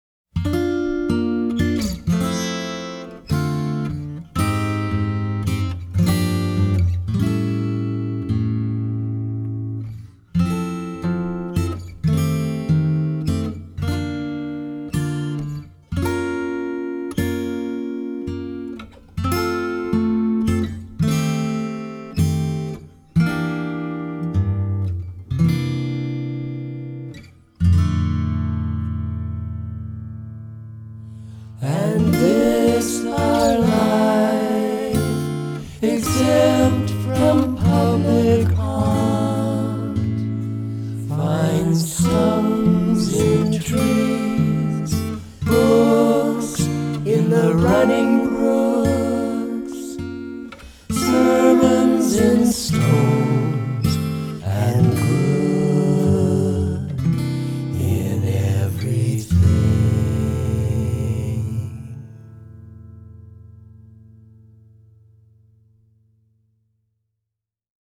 guitar, vocal
• Recorded in Winnipeg in September, 2021